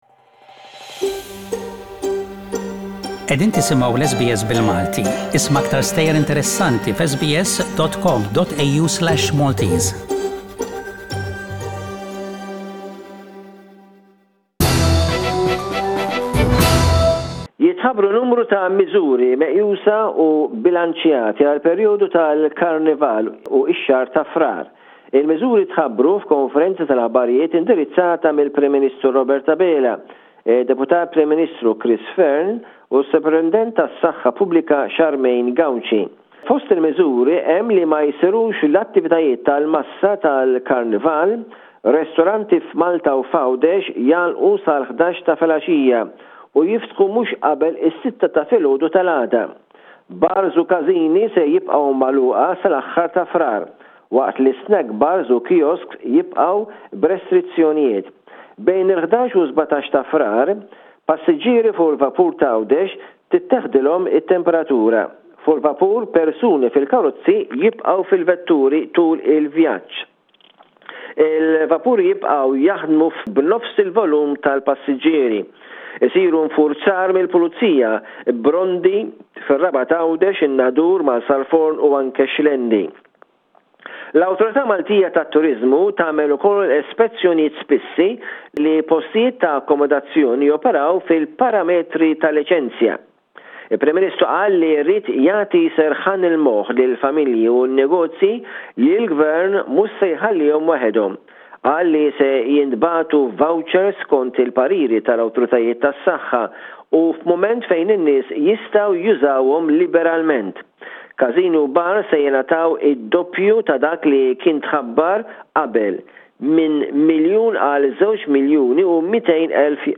SBS Radio correspondent